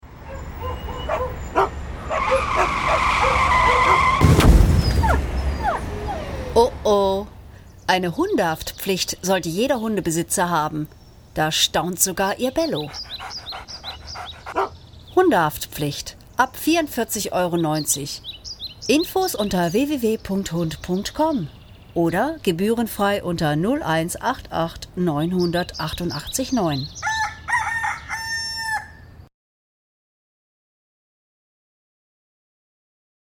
Schauspielerin, Sprecherin
Sprechprobe: Sonstiges (Muttersprache):